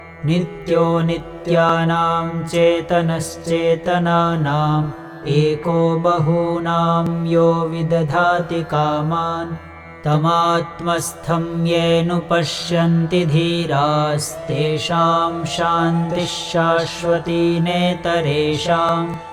Mantra